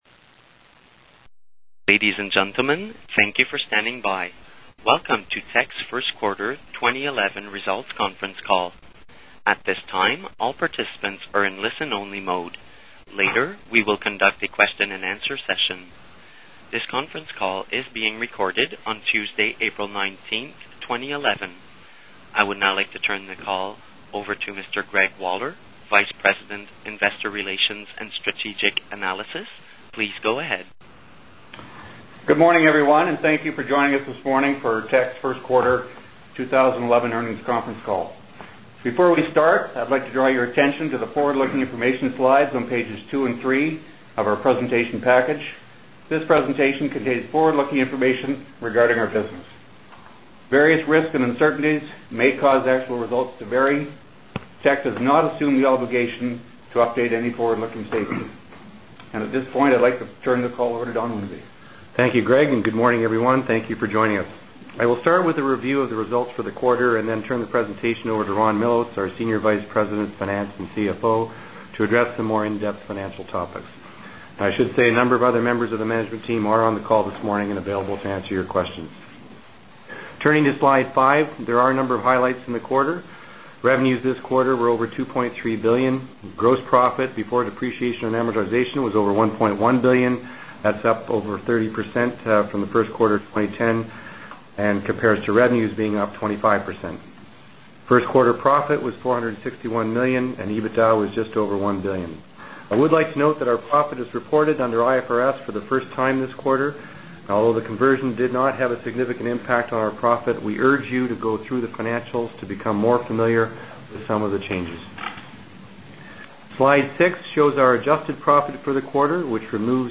Q1 2011 Financial Report Conference Call Audio File